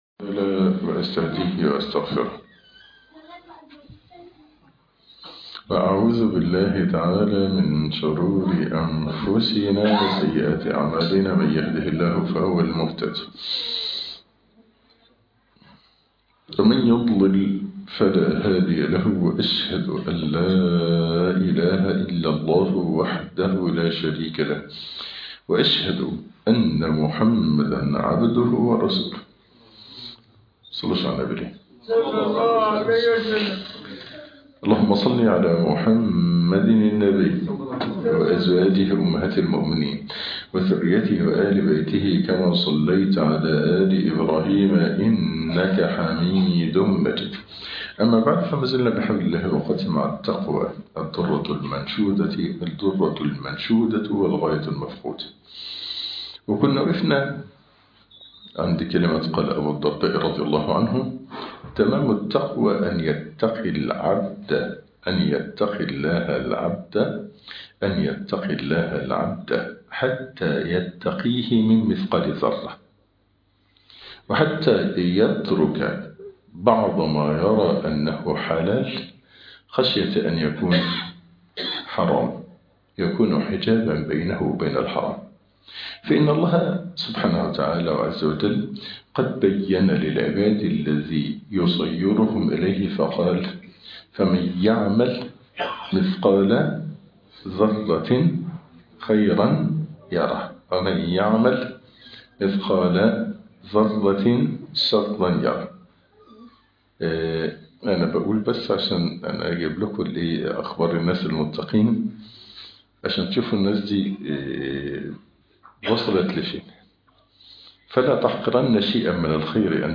( شرح كتاب التقوى ) الدرس الحادي عشر